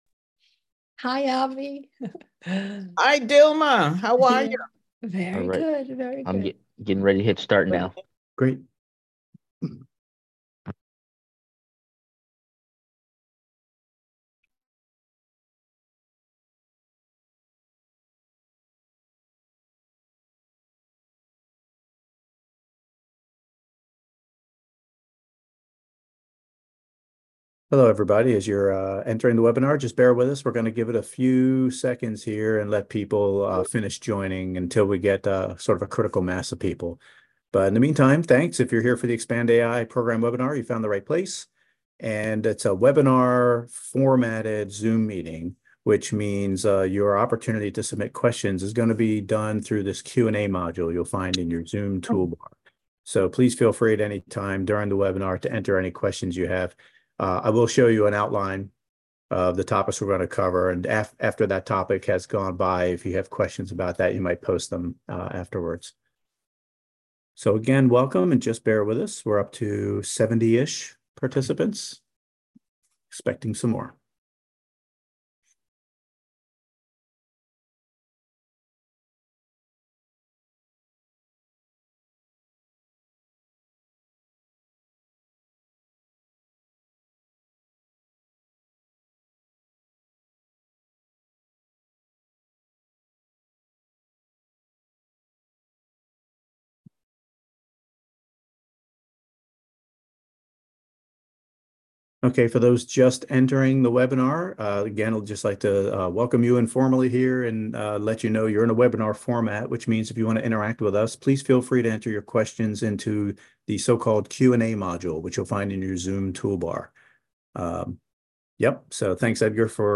ExpandAI 2024 Webinar